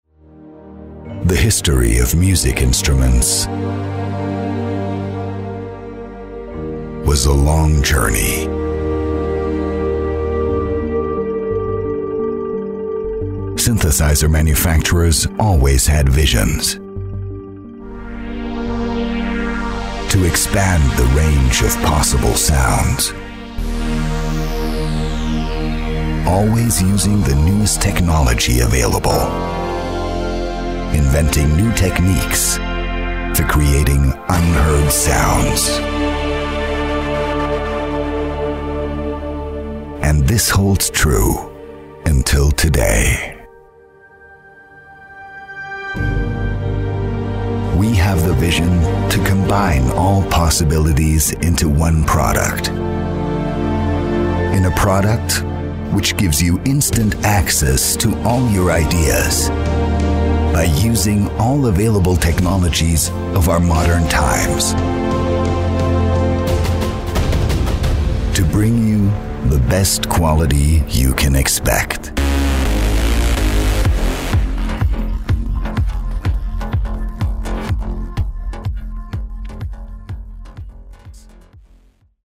Dank seiner angenehmen sonoren Tonlage kommt er bei den Kunden immer hervorragend an.
Dokumentation